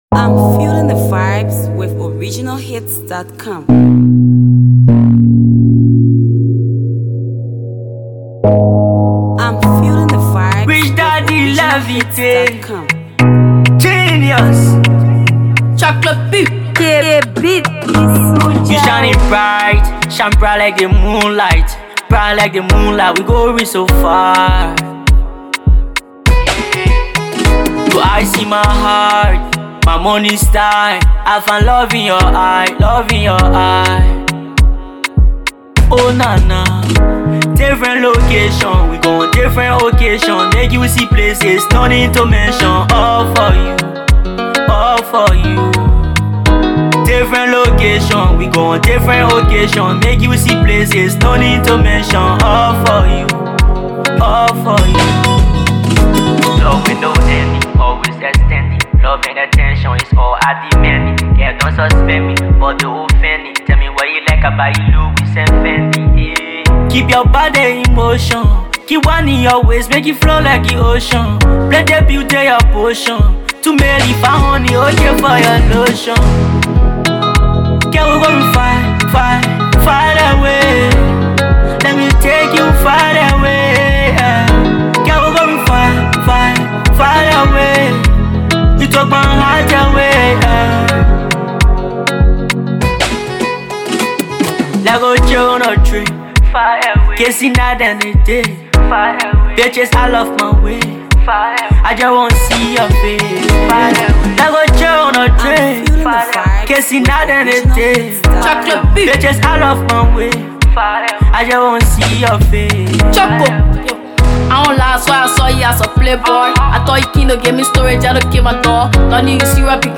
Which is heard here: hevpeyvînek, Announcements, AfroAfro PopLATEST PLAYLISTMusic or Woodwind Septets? AfroAfro PopLATEST PLAYLISTMusic